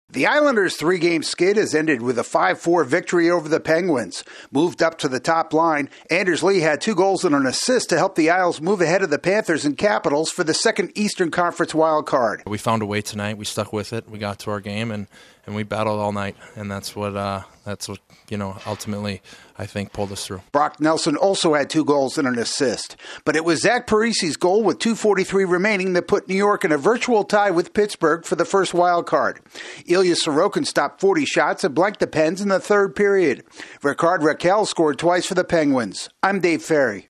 The Islanders move up two spots in the Eastern Conference standings. AP correspondent